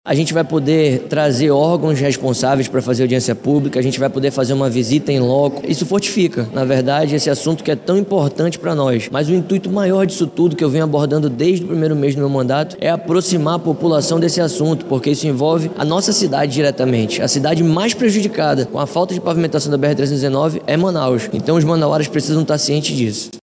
Autor do Projeto: vereador João Paulo Janjão (Foto Divulgação)
O autor do projeto explica que a principal meta é levar a discussão para o seio da sociedade.